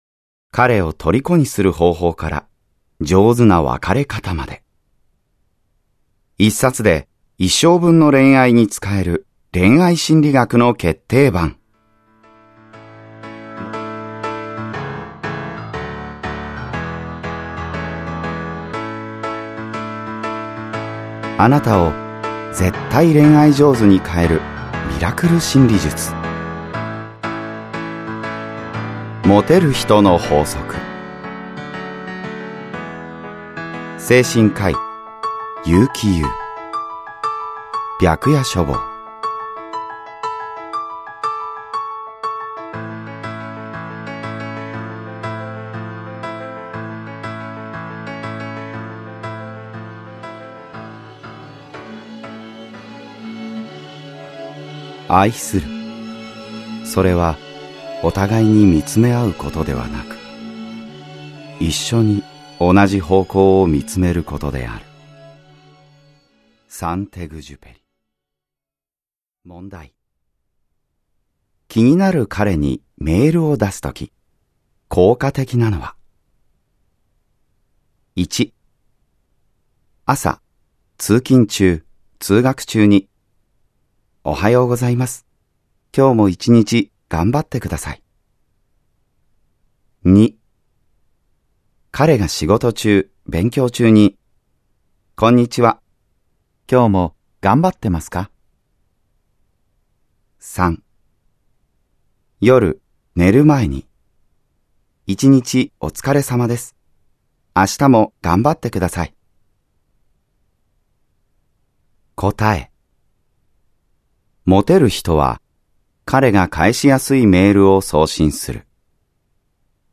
[オーディオブックCD] モテる人の法則